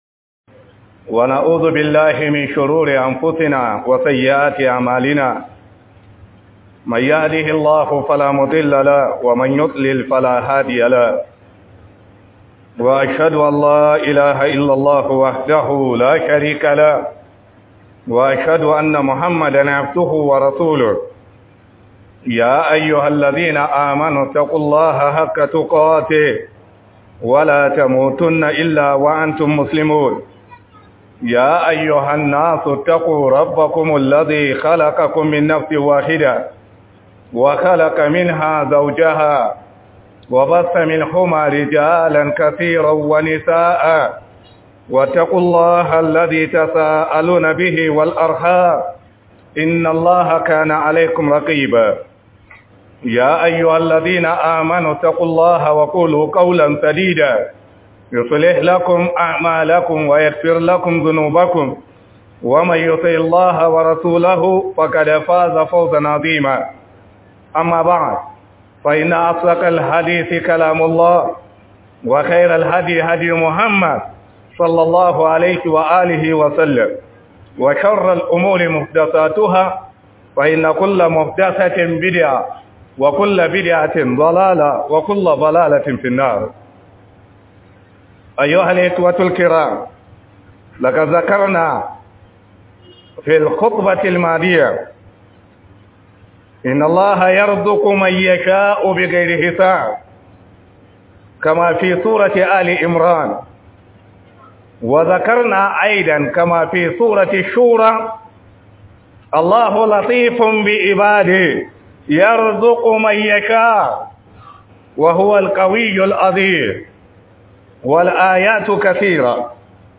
Godiya Ga Allah bisa Ni'imar Ruwan Sama - Huduba